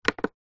Беспроводное соединение Bluetooth, динамик, нажатие кнопки 1.
besprovodnoe-soedinenie-bluetooth-dinamik-nazhatie-knopki-1.mp3